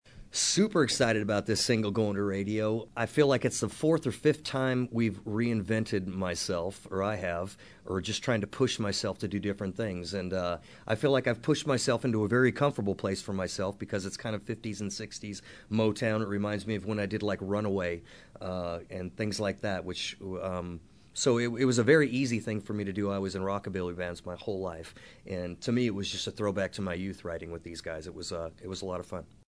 Audio / AUDIO: Gary Allan says he’s really excited for folks to hear his new single, “Hangover Tonight.”